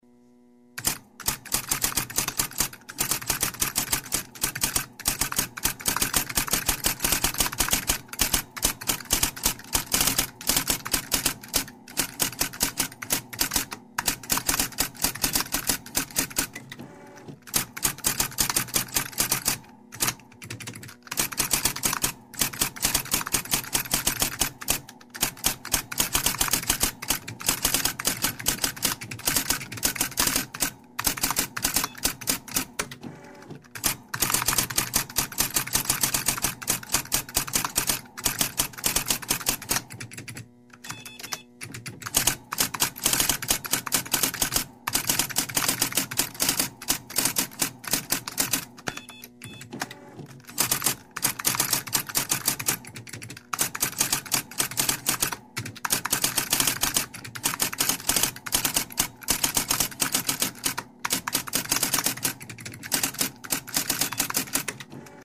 Здесь собраны характерные эффекты: от ритмичного стука клавиш до металлического звона каретки.
Печать на старой машинке